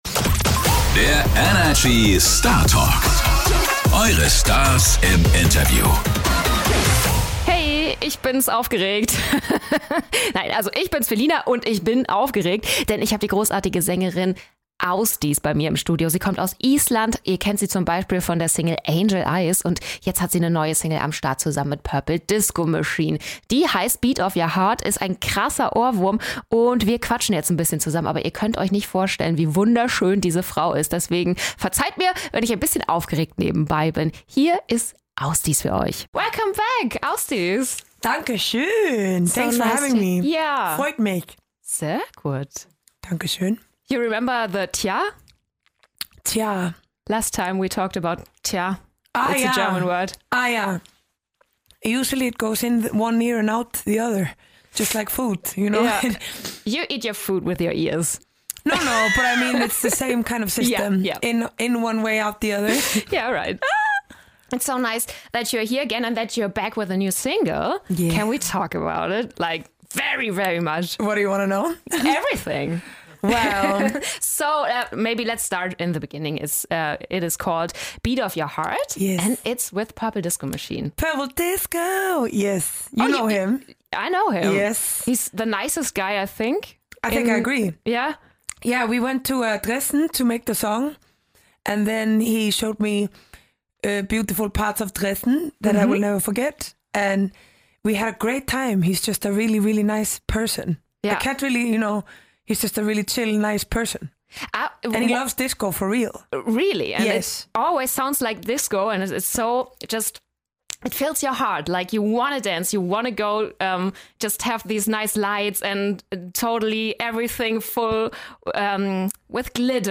Beschreibung vor 2 Jahren In der neuen Startalk-Folge ist die isländische Sängerin Ásdís zu Gast!